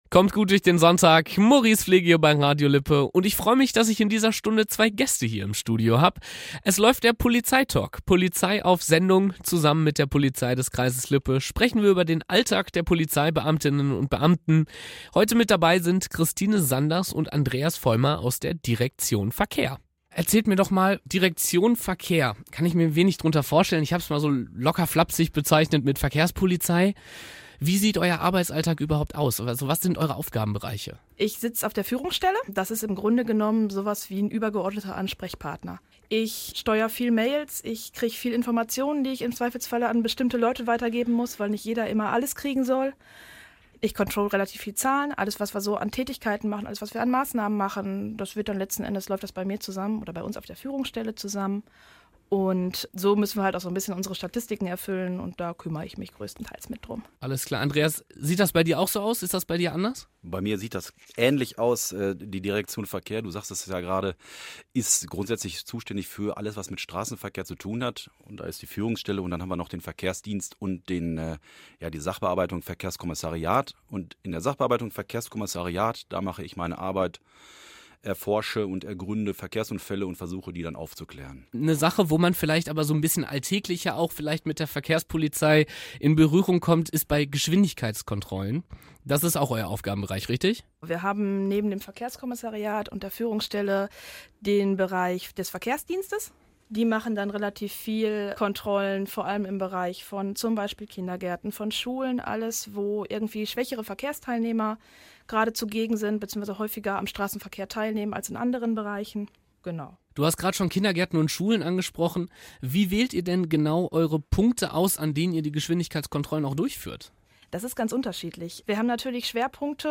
Radio-Talk